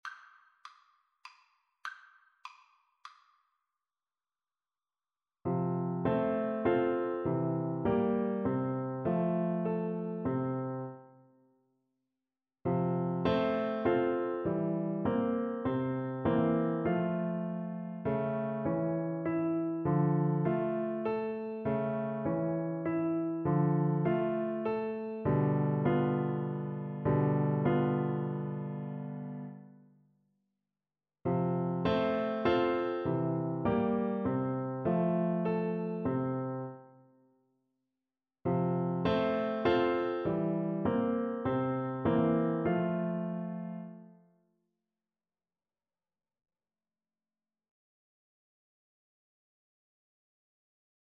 Trumpet
Arrangement for Trumpet and Piano
F major (Sounding Pitch) G major (Trumpet in Bb) (View more F major Music for Trumpet )
3/4 (View more 3/4 Music)
Allegro moderato (View more music marked Allegro)
Classical (View more Classical Trumpet Music)